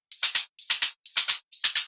Index of /VEE/VEE2 Loops 128BPM
VEE2 Electro Loop 191.wav